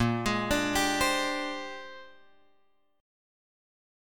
A# 13th